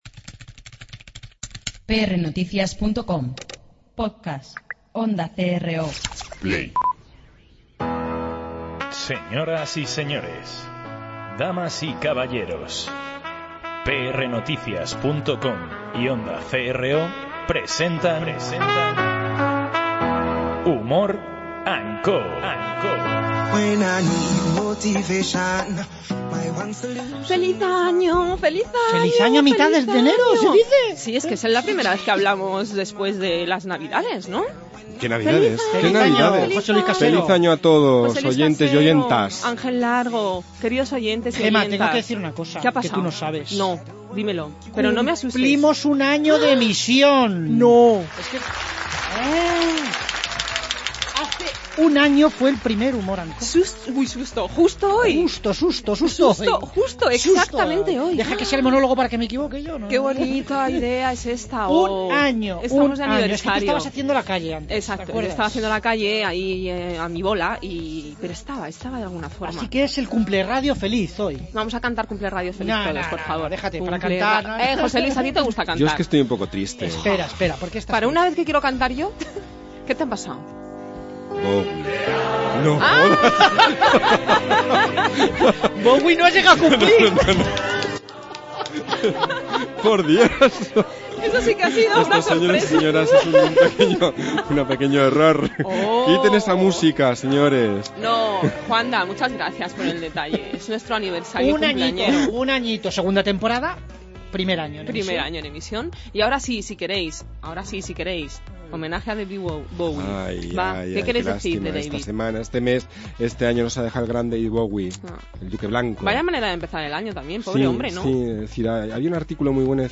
Mientras te piensas qué significa para ti el liderazgo femenino, te dejamos con nuestro humorista de cabecera.